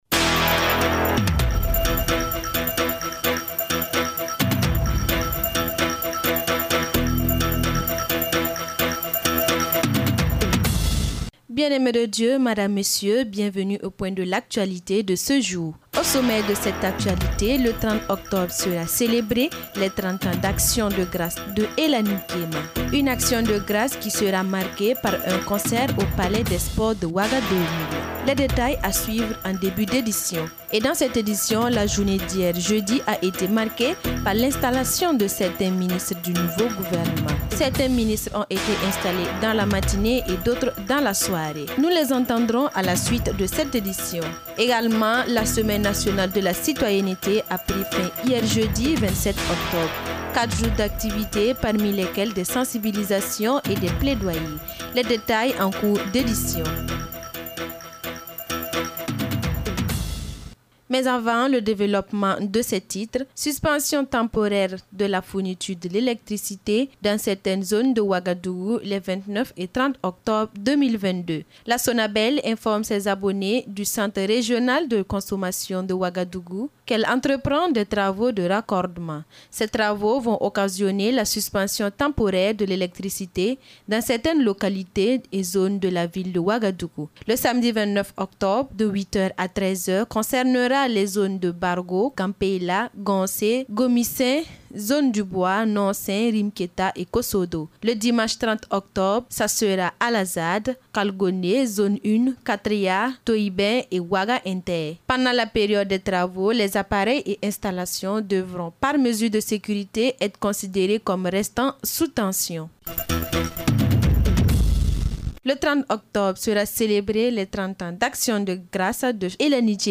Les titres du journal parlé de 12h de ce vendredi 28 octobre 2022